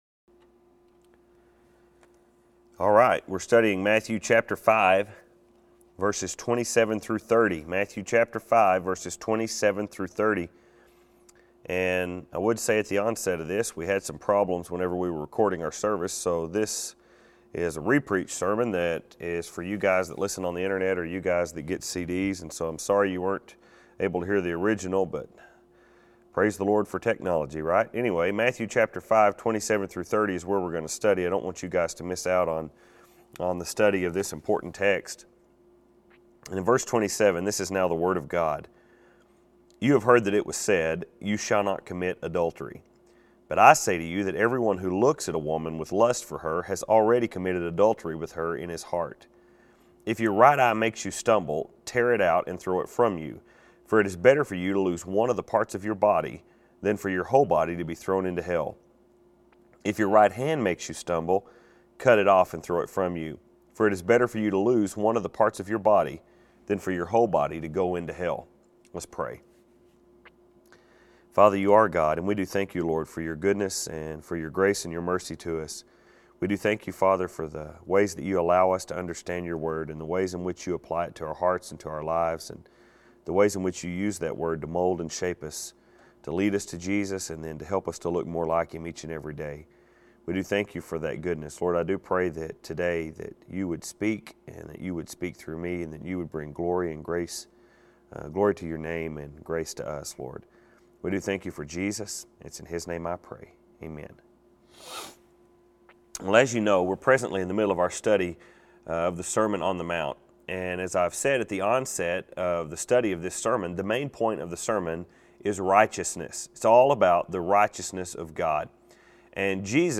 And as I told you at the very onset of this sermon, The main point of the sermon is RIGHTEOUSNESS. Jesus is confronting a people Who have about a twisted a view of righteousness as you can have.